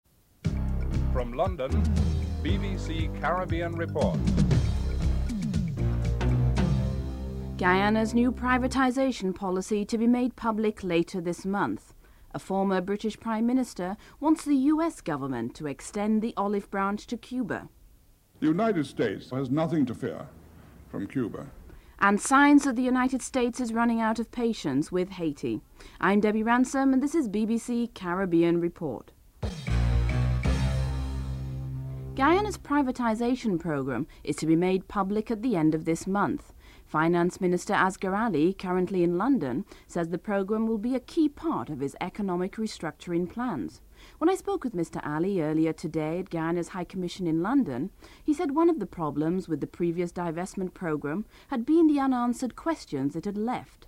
2. Former British Prime Minister Sir Edward Heath called on the US government to end its economic blockade against Cuba speaking at a Euro Money Seminar in London – entitled - “Investment in Cuba.” (05:55 – 10:03).